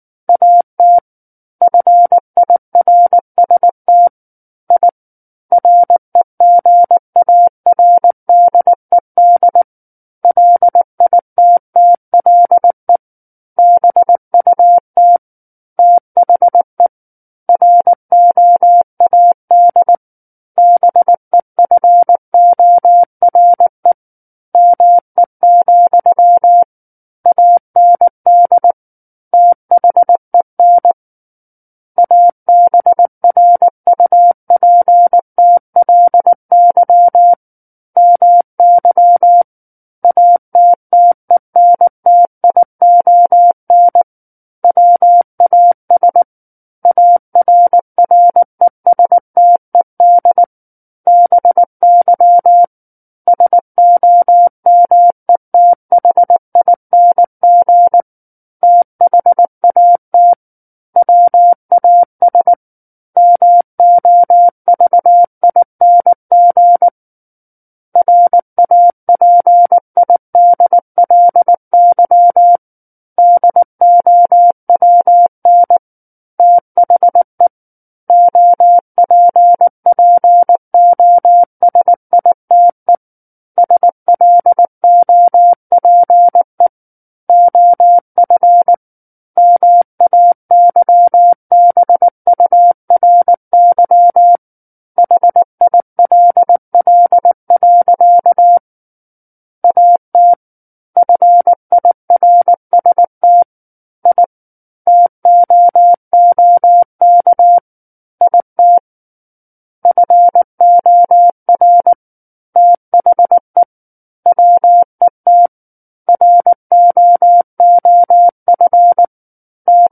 War of the Worlds - 10-Chapter 10 - 19 WPM